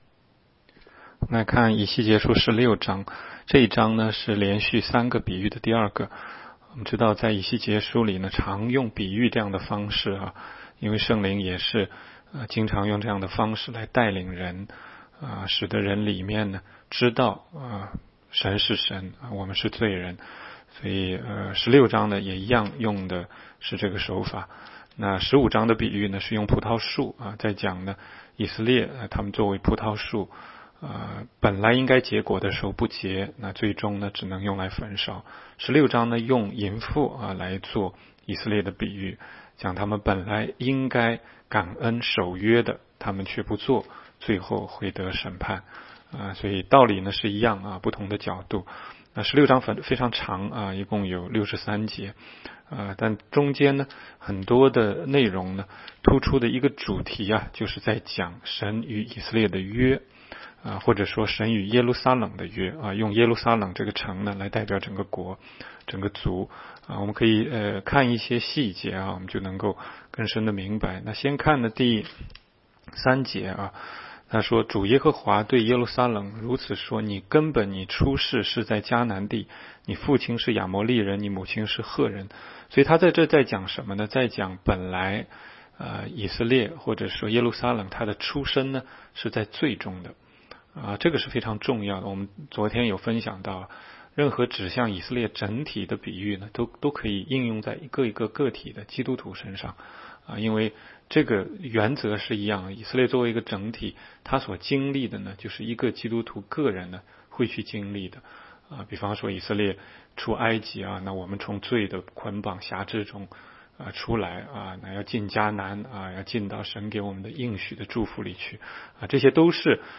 16街讲道录音 - 每日读经 -《以西结书》16章